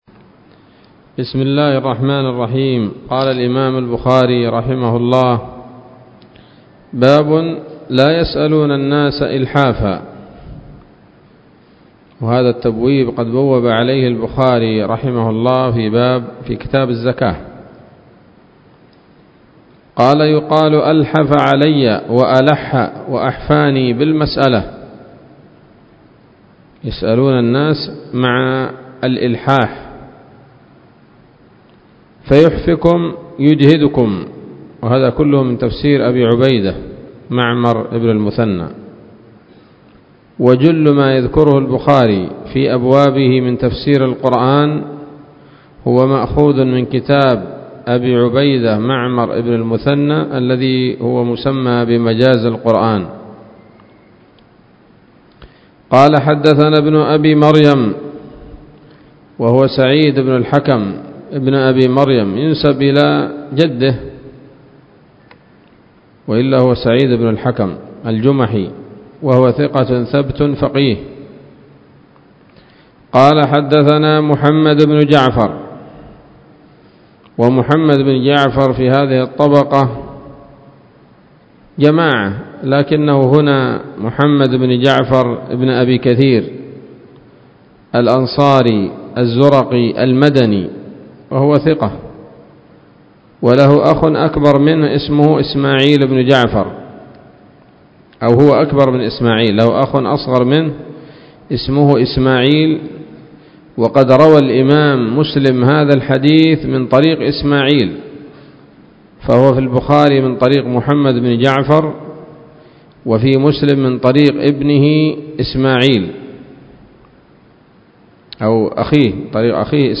الدرس الحادي والأربعون من كتاب التفسير من صحيح الإمام البخاري